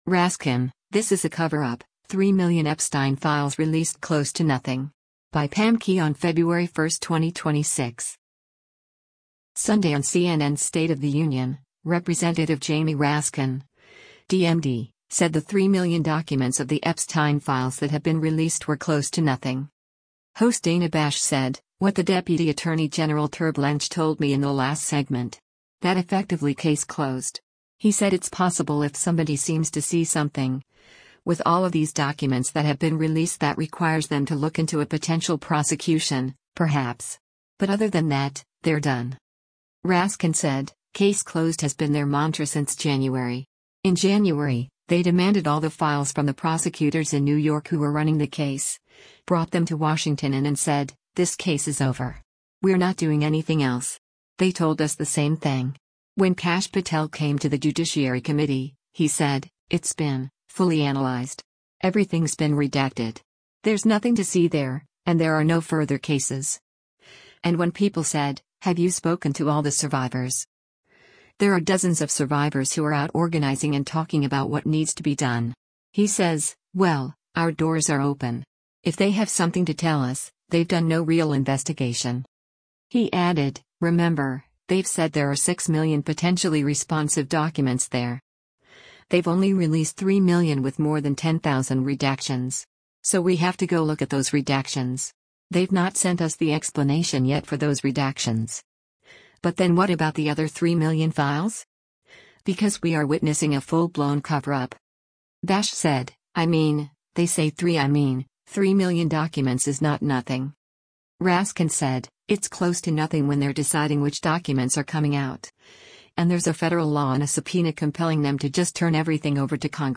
Sunday on CNN’s “State of the Union,” Rep. Jamie Raskin (D-MD) said the 3 million documents of the Epstein files that have been released were “close to nothing.”